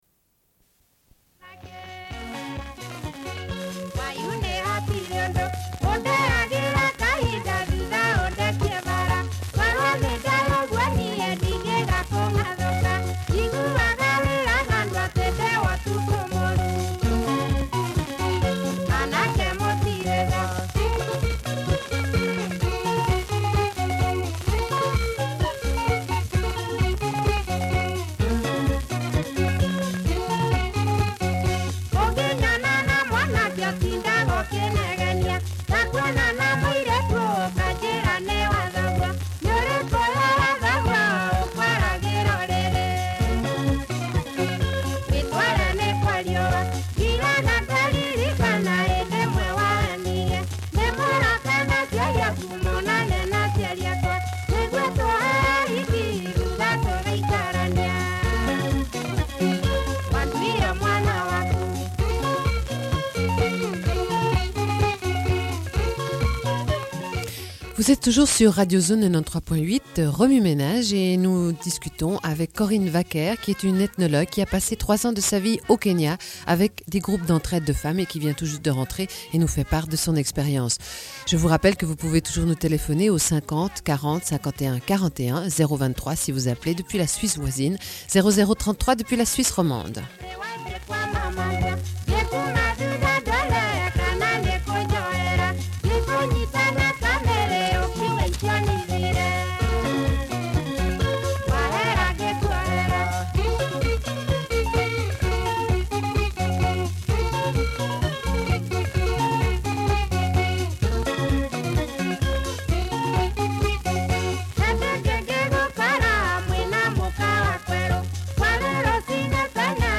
Une cassette audio, face B00:46:39
Radio Enregistrement sonore